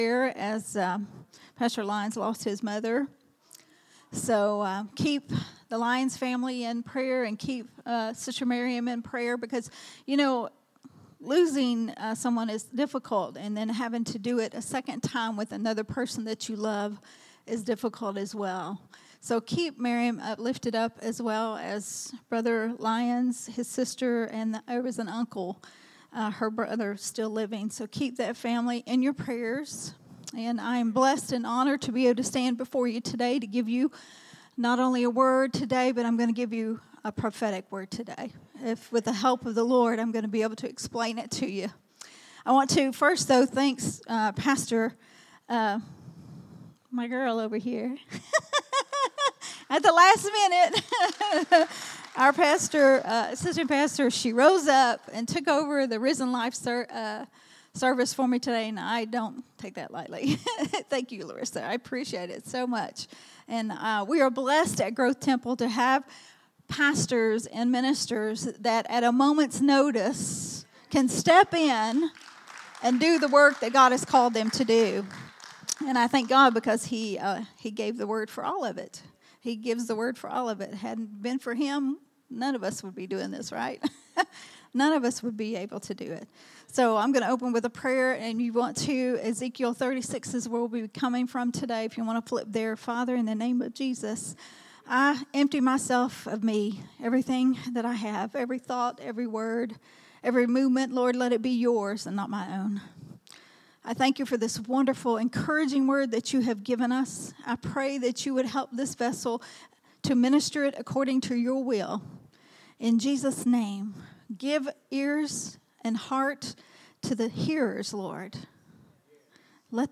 Sunday Morning Worship Service at Growth Temple Ministries.